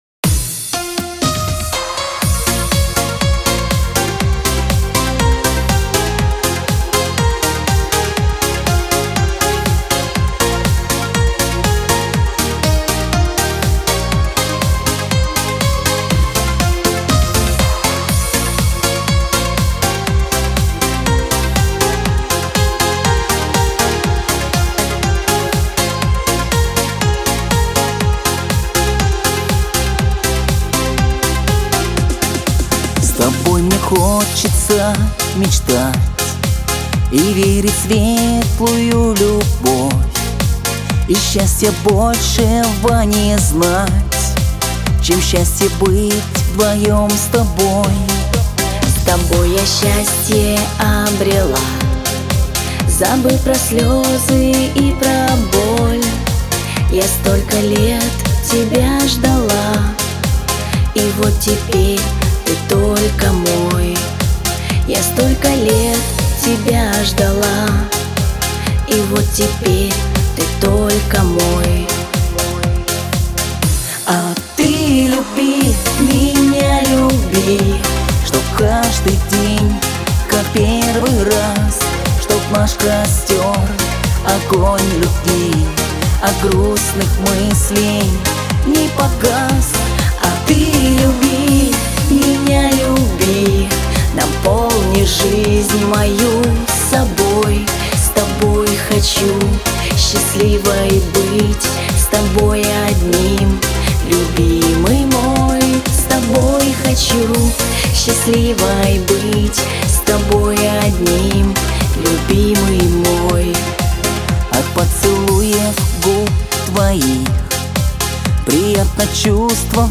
Лирика
дуэт
диско